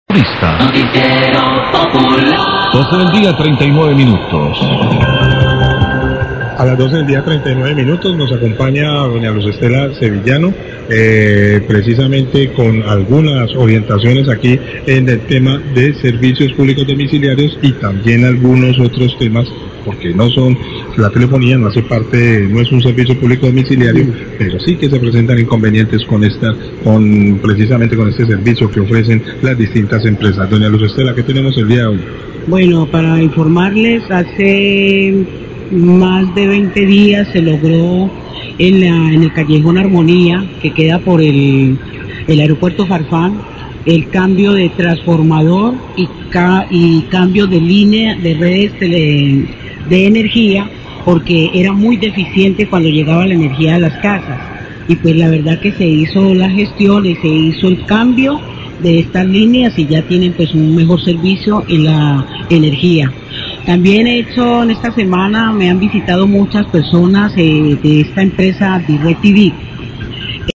VOCAL DE CONTROL DE SERVICIOS PÚBLICOS RESPONDE DUDAS DE LOS OYENTES, LA CARIÑOSA, 1240PM
Radio